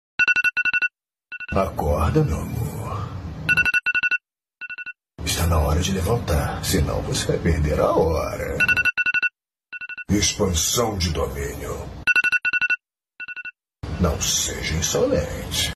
Toque de despertador com a voz de Sukuna
Toque do iPhone 14 original Som de alarme do Sukuna
Categoria: Toques
toque-de-despertador-com-a-voz-de-sukuna-pt-www_tiengdong_com.mp3